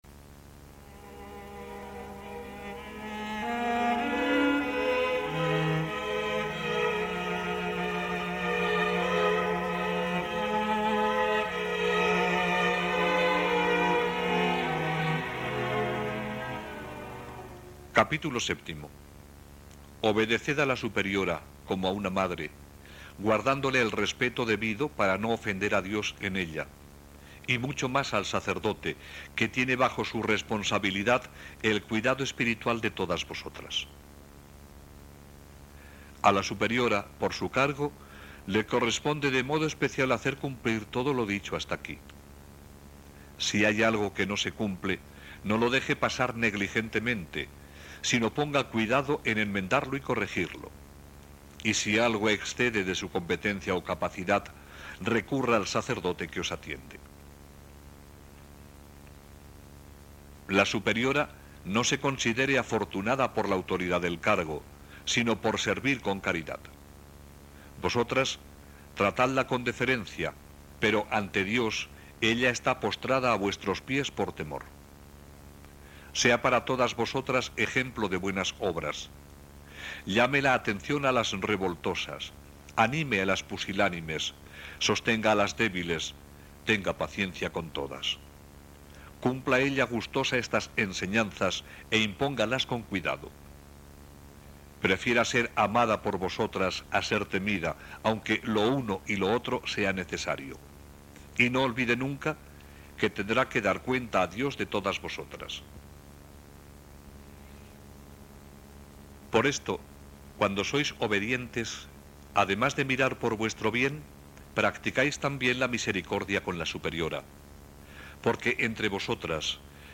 Voz de hombre.